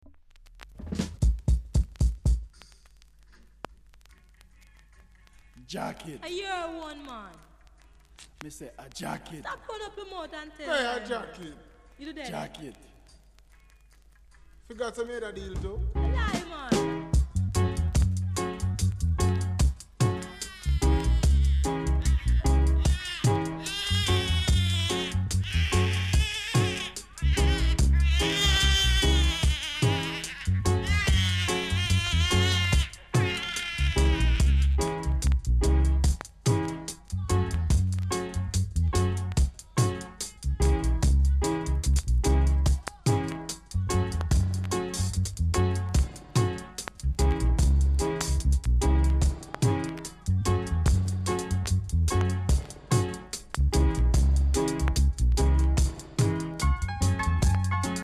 ※VERSIONサイドにパチノイズあります。